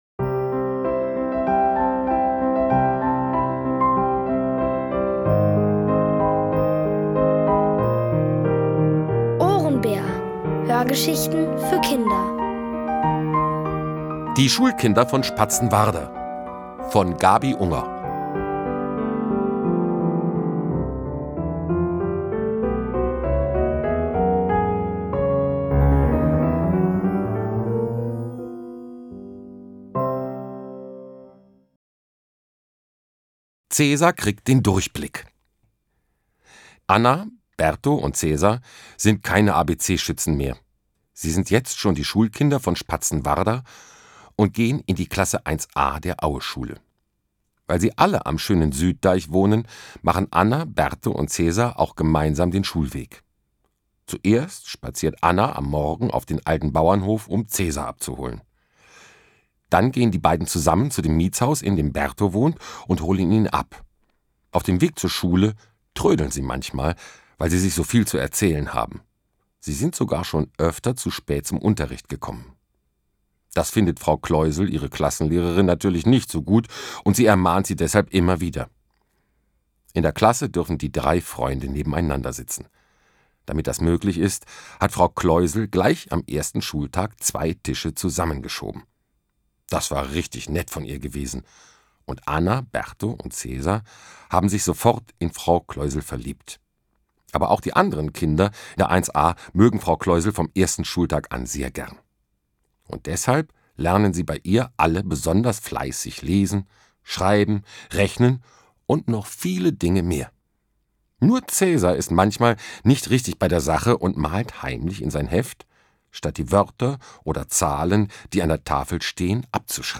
Von Autoren extra für die Reihe geschrieben und von bekannten Schauspielern gelesen.
Kinder & Familie